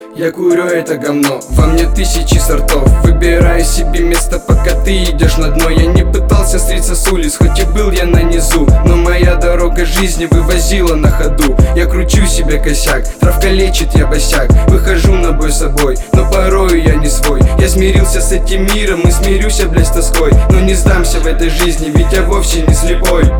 • Качество: 320, Stereo
качающие